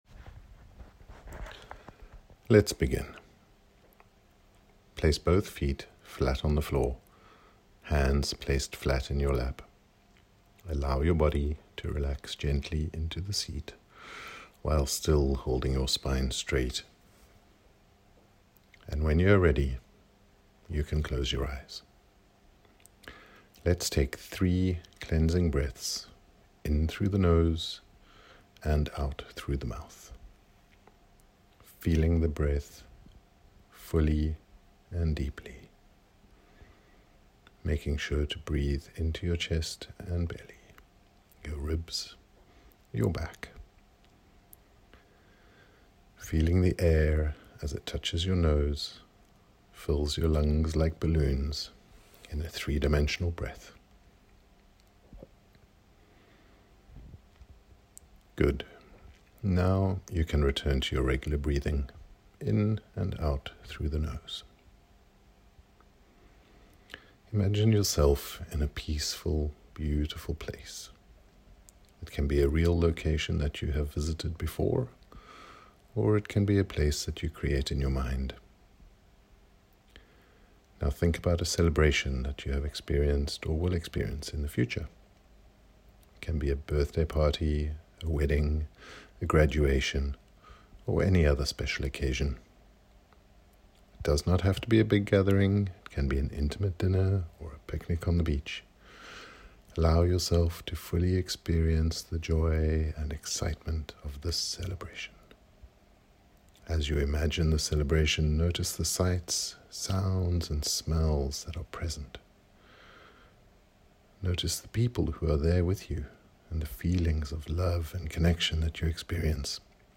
Printing Party Meditation